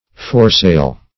Foresail \Fore"sail`\, n. (Naut.)